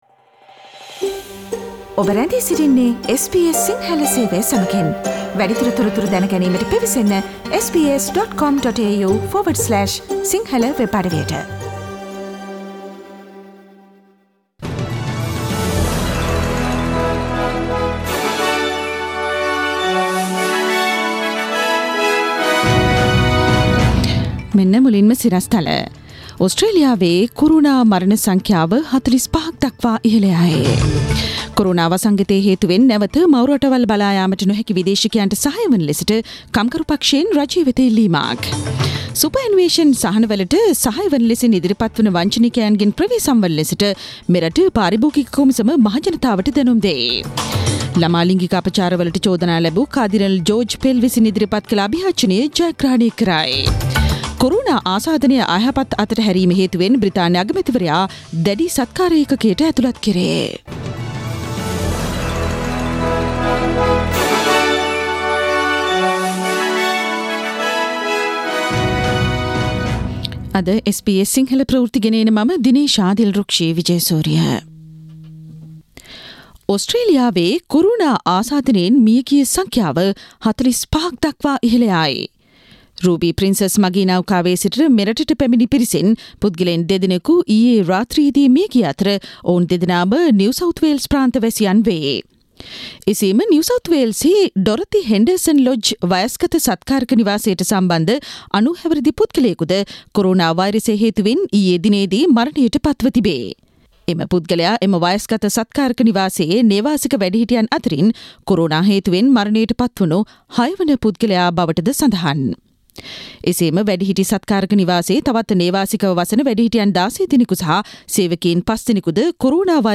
Daily News bulletin of SBS Sinhala Service: 7 April 2020
Today’s news bulletin of SBS Sinhala radio – Tuesday 7 April 2020.